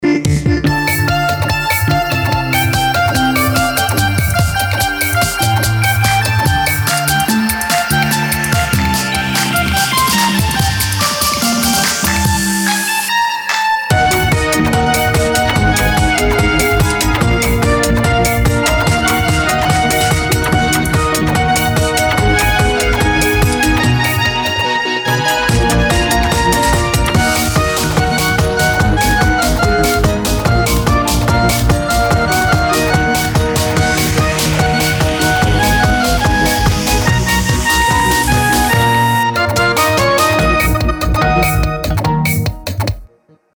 • Качество: 320, Stereo
поп
ритмичные
веселые
без слов
инструментальные
Веселая позитивная музыка.